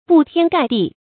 布天蓋地 注音： ㄅㄨˋ ㄊㄧㄢ ㄍㄞˋ ㄉㄧˋ 讀音讀法： 意思解釋： 形容數量極多，散布面極廣。